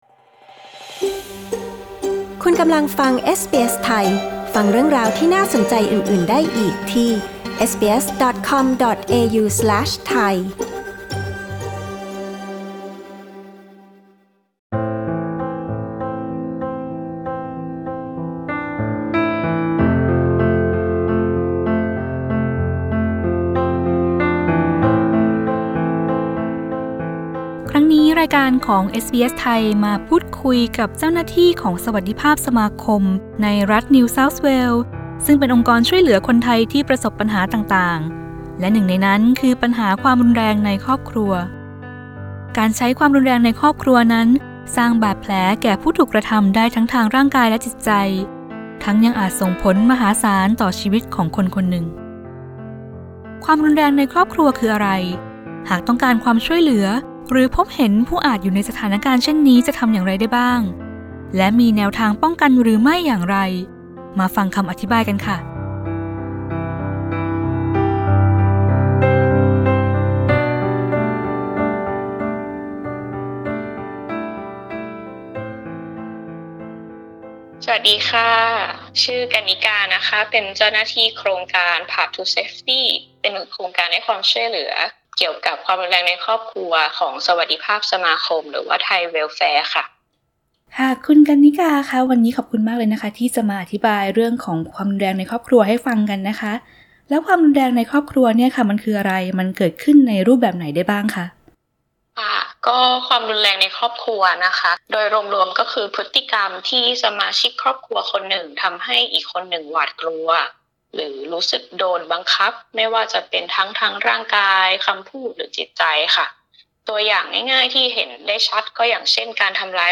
เอสบีเอส ไทย พูดคุยกับเจ้าหน้าที่โครงการ Paths to Safety ของสวัสดิภาพสมาคม